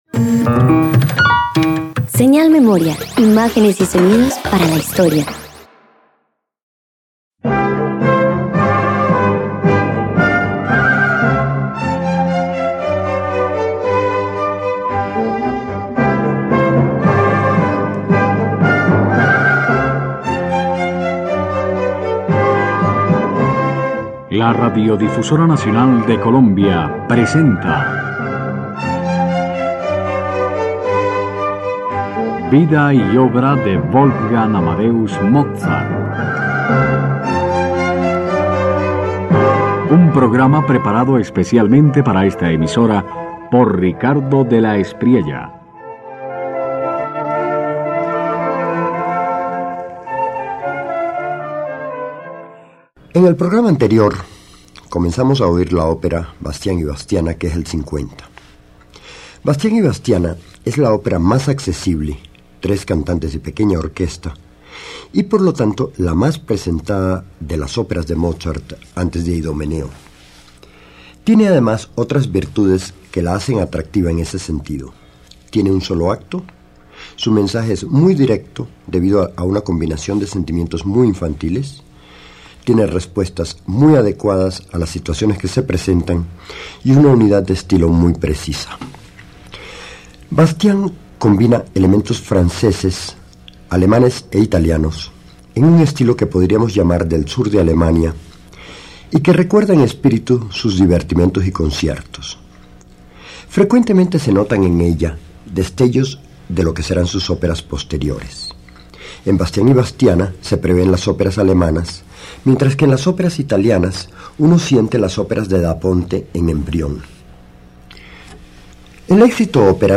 Mozart intensifica aquí el diálogo de los personajes, alternando la ternura y la confusión en melodías breves y claras que respiran humanidad. No se complace en adornar lo rústico, sino que lo vuelve centro de un teatro pequeño y profundo a la vez.